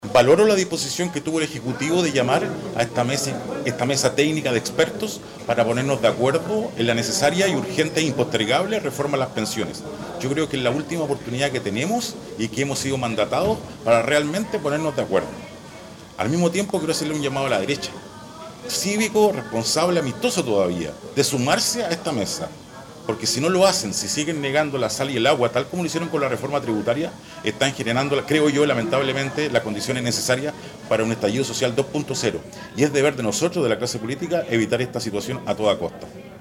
En representación de la Bancada PPD- Independientes asistió el diputado Héctor Ulloa, quien valoró la disposición del Ejecutivo de llamar a esta mesa técnica de expertos: